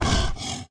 Npc Raccoon Run Sound Effect
npc-raccoon-run-1.mp3